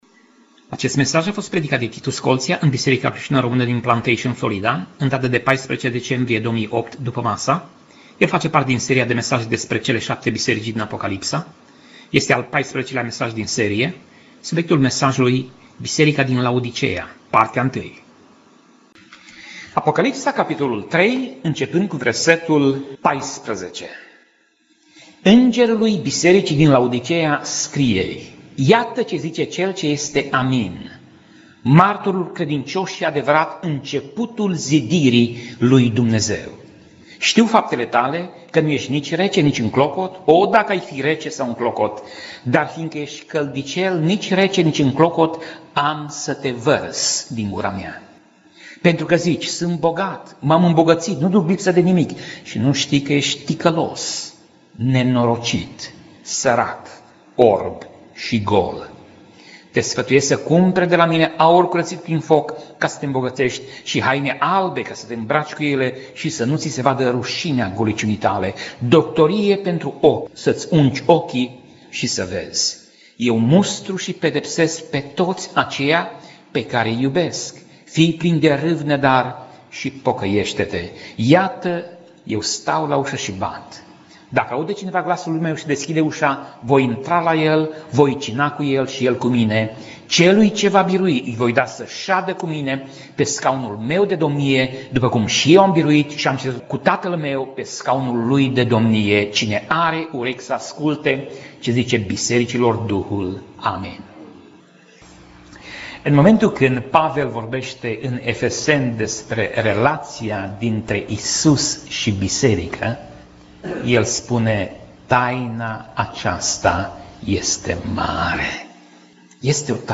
Pasaj Biblie: Apocalipsa 3:14 - Apocalipsa 3:22 Tip Mesaj: Predica